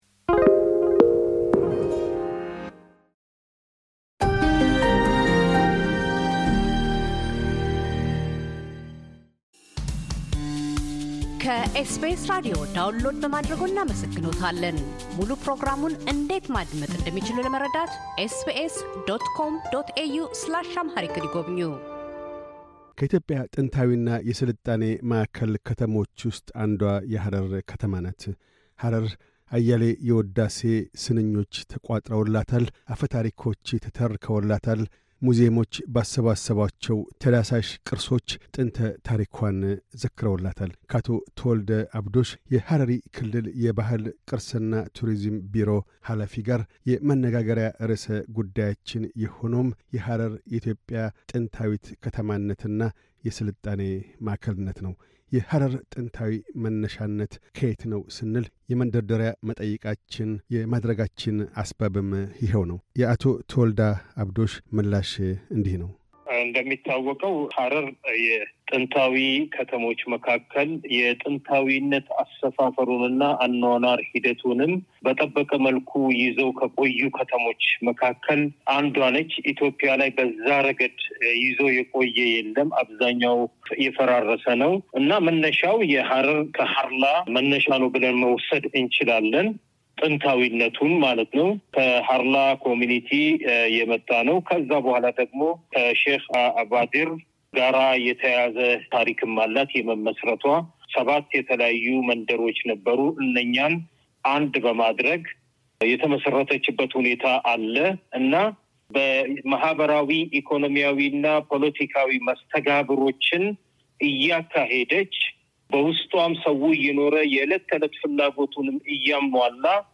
ምልሰታዊ ምልከታ 2023፤ አቶ ተወለዳ አብዶሽ - የሐረሪ ክልል የባሕል፣ ቅርስና ቱሪዝም ቢሮ ኃላፊ፤ የሐረር ከተማን ጥንታዊ ታሪካዊነትና የቱሪዝም መስህቦችን አስመልክተው ይናገራሉ።